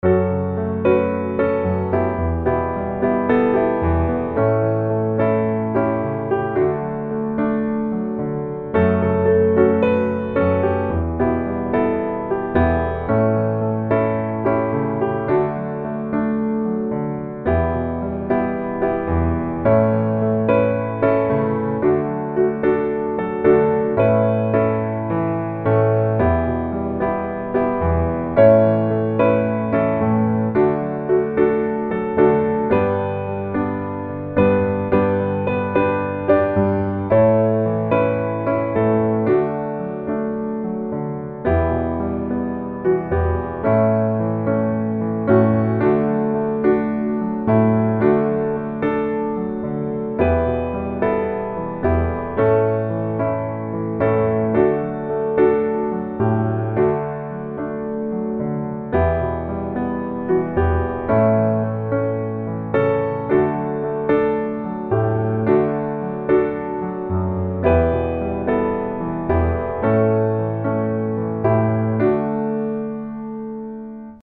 D Majeur